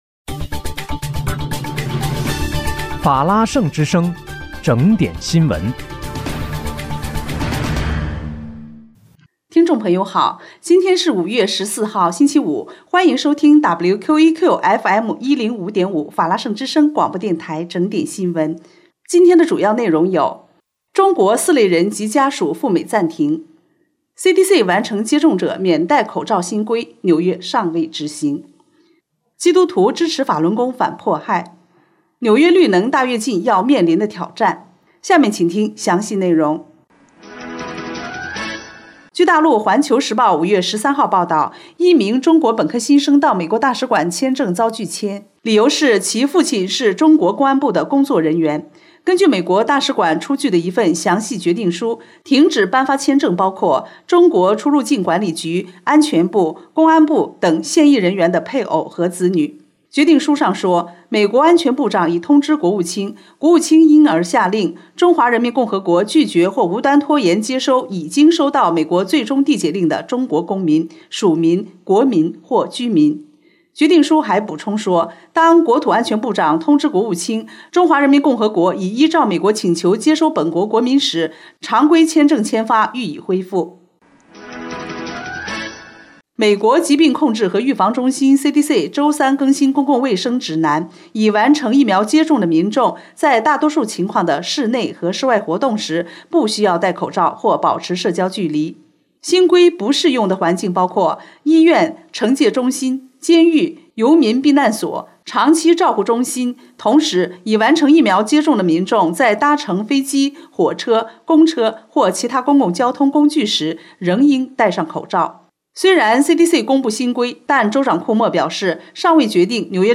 5月14日（星期五）纽约整点新闻
听众朋友您好！今天是5月14号，星期五，欢迎收听WQEQFM105.5法拉盛之声广播电台整点新闻。